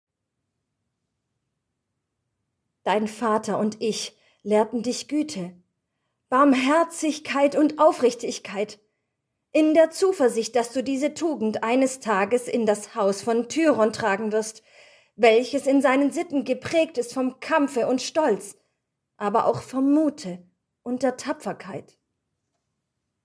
Spoiler: Demo aus dem Hörspiel your_browser_is_not_able_to_play_this_audio Hallo liebe Freunde der HT-Community.